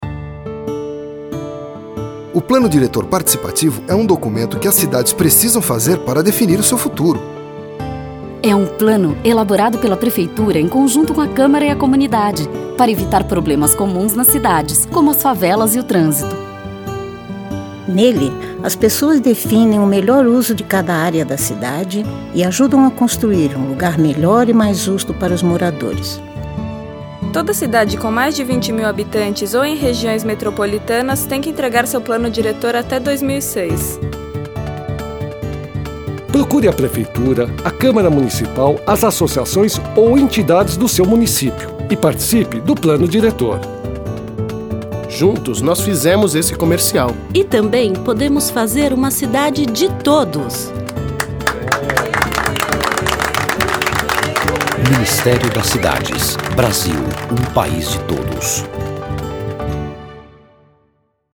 Spots de radio